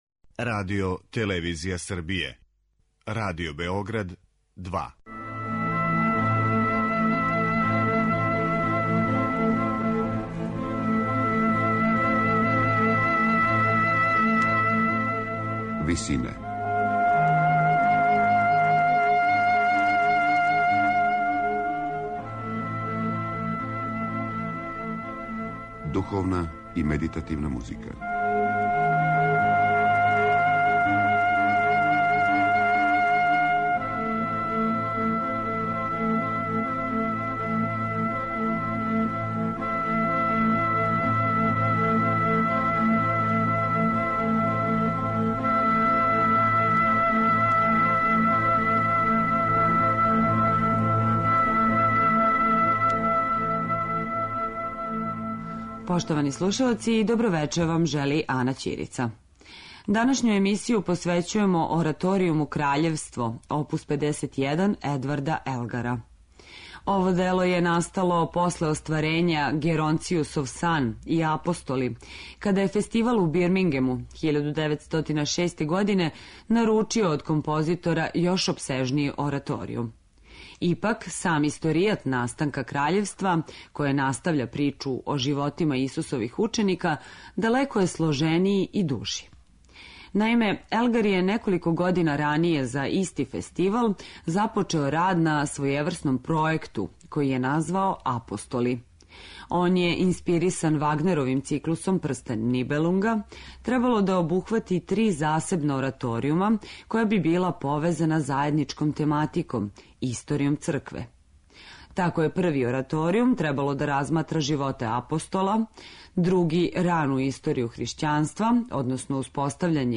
Едвард Елгар: ораторијум Краљевство, оп.51
Компоновано за двоструки хор, велики, позноромантичарски састав оркестра и четворо солиста, Краљевство је било замишљено као централни део грандиозног и недовршеног Елгаровог пројекта - ораторијумске трилогије Апостоли.